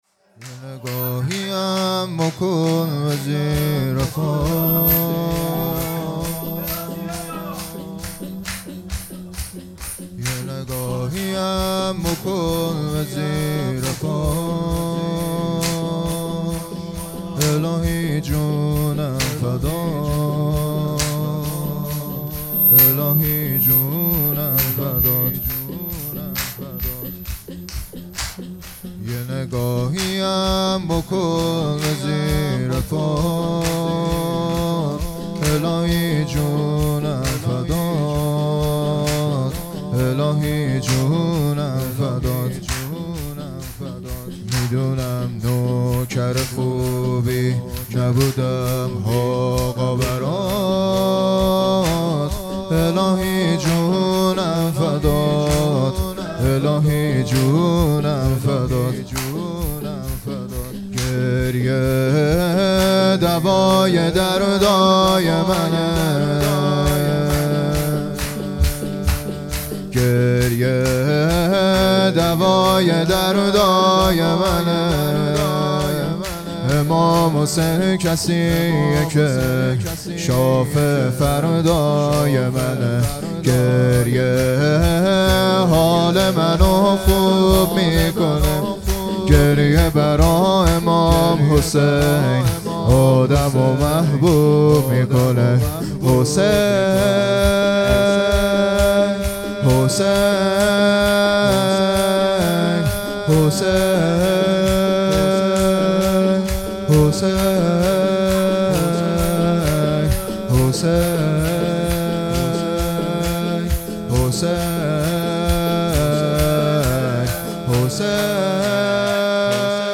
هیئت بیت العباس (ع) اسلامشهر
📒 شور 🏴 یه نگاهی هم بکن ...
شب چهارم محرم الحرام 1446